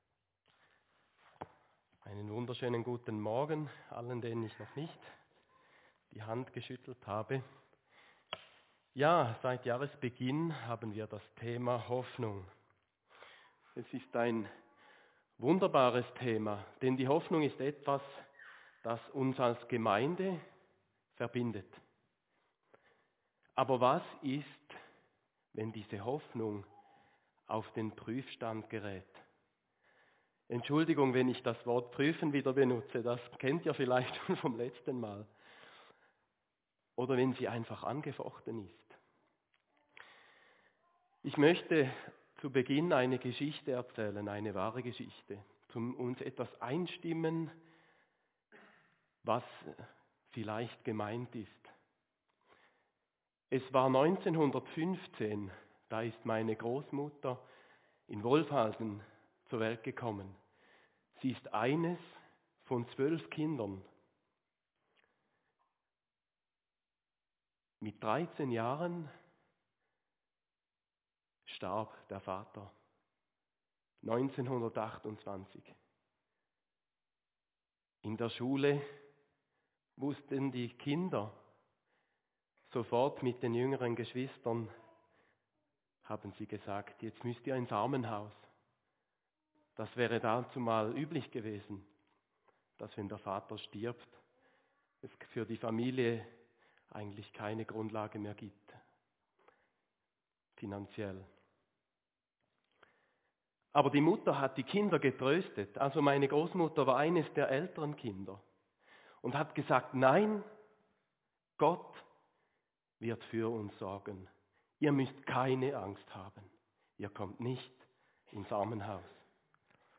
Predigt-23.-6.24.mp3